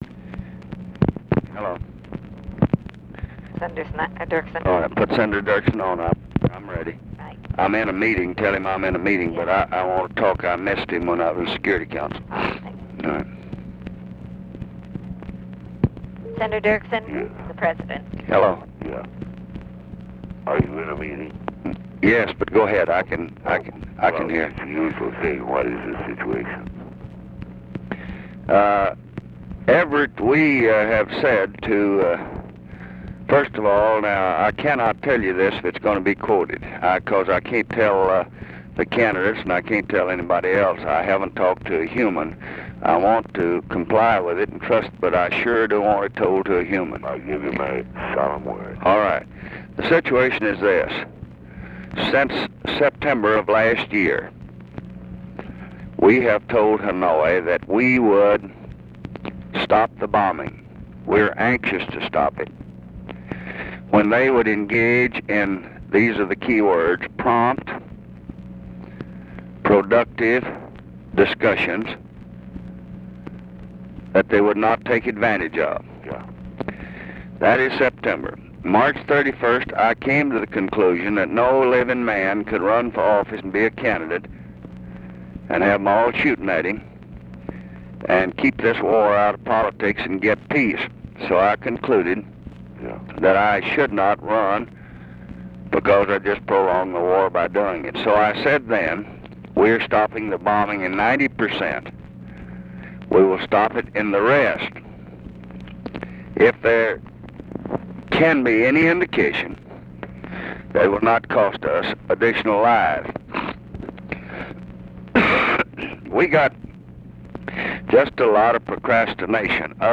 Conversation with EVERETT DIRKSEN and TELEPHONE OPERATOR, October 31, 1968
Secret White House Tapes